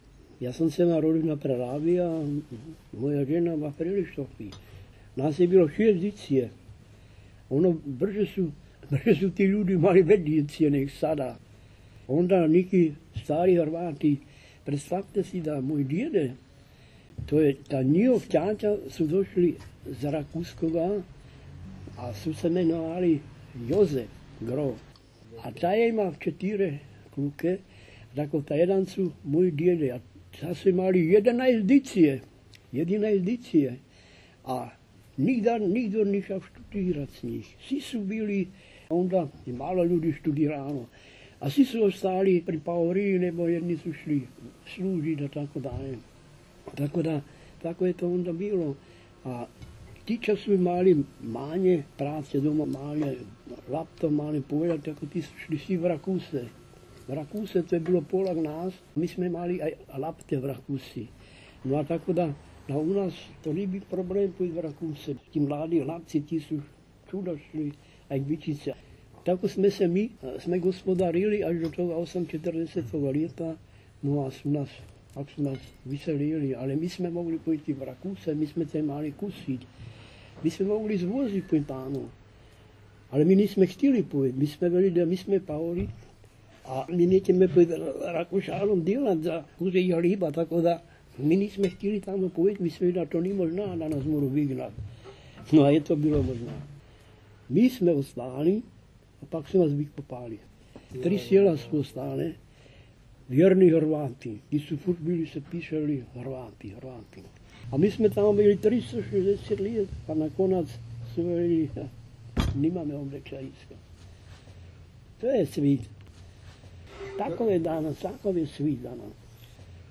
Moravska 1 – Govor
Moravski Hrvati, Mährischen Kroaten, Mähren, Tschechien
Moravska 2008.